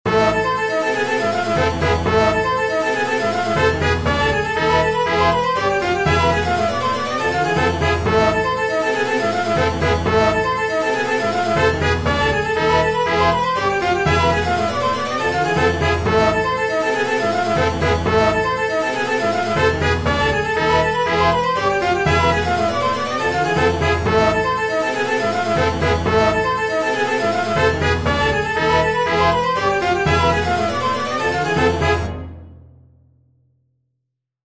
Short 120bpm loop in 13edo
13edo_demo.mp3